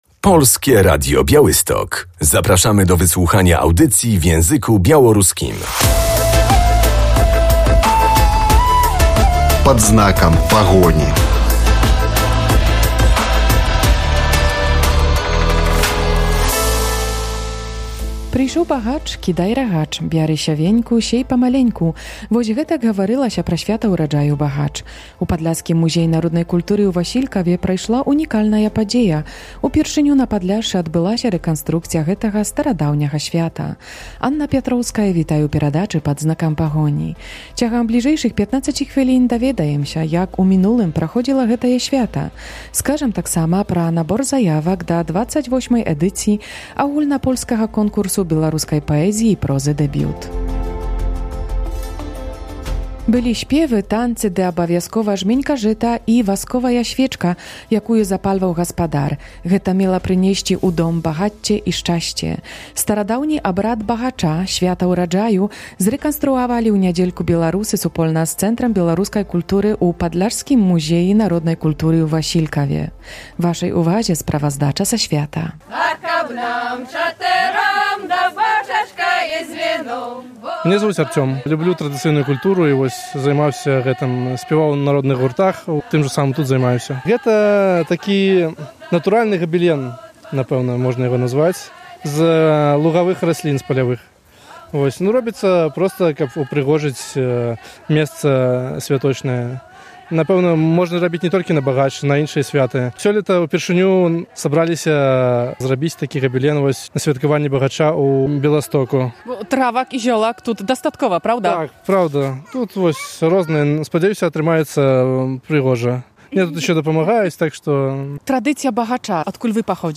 Były śpiewy, tańce, wspólna integracja i tradycyjny obrzęd "Bahacza" . W Podlaskim Muzeum Kultury Ludowej w Wasilkowie po raz pierwszy odbyło się święto urodzaju - czyli "Bahacz".